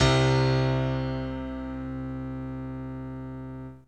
04 SY99 Piano C2.wav